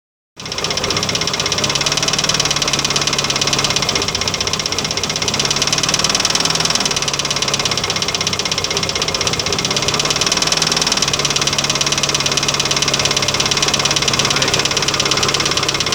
Welche Frequenz hat das tackern?